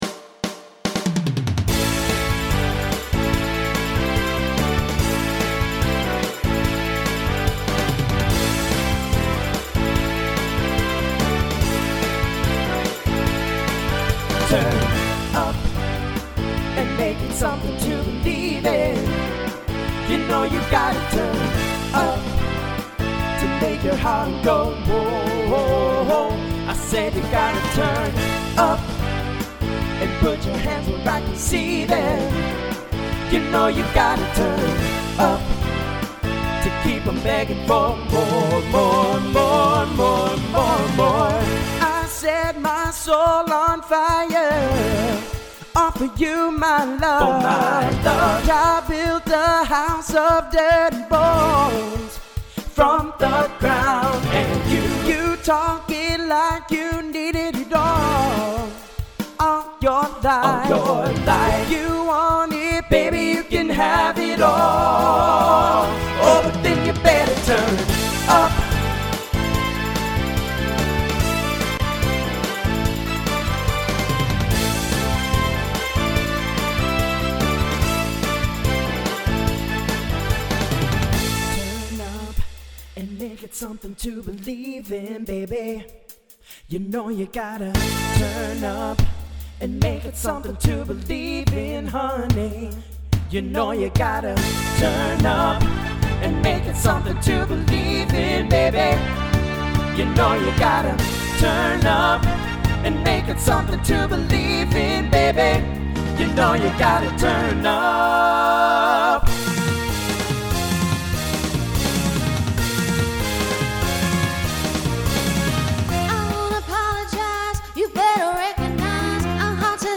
TTB/SSA/SATB
Voicing Mixed Instrumental combo Genre Pop/Dance